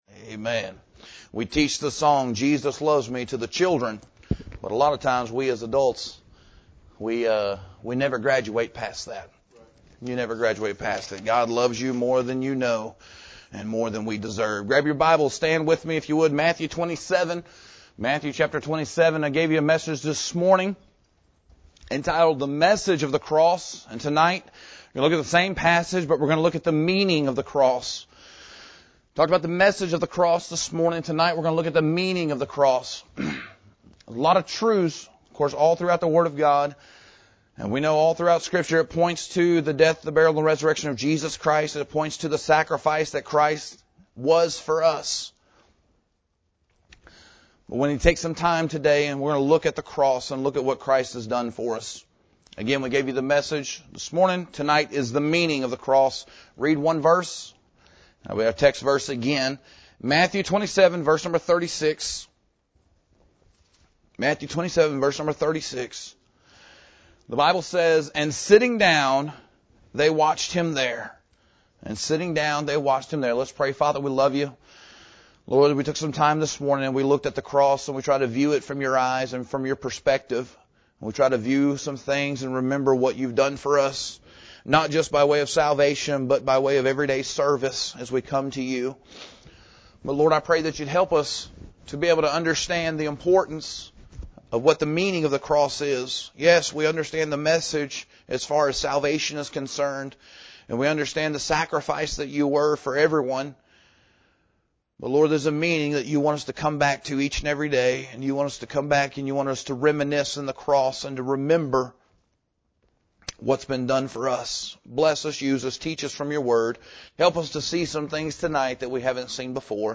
The Meaning of the Cross – Cornerstone Baptist Church | McAlester, OK